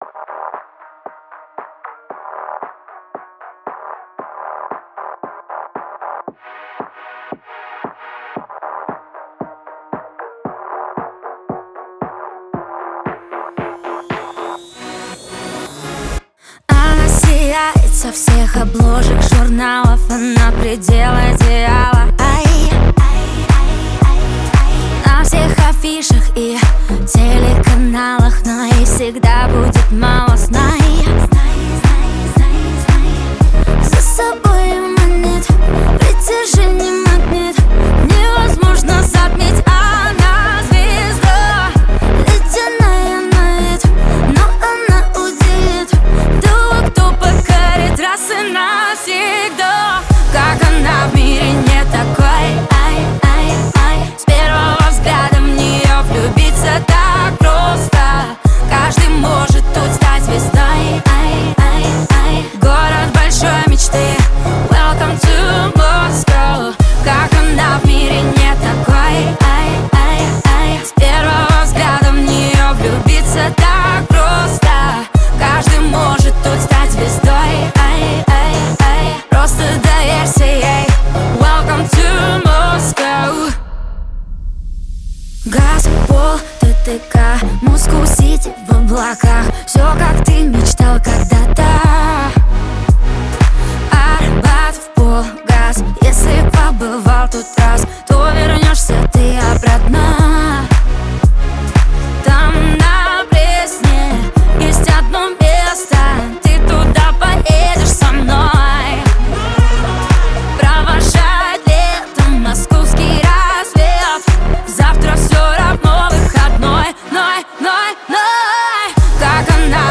Качество: 320 kbps, stereo
Русские поп песни, Поп музыка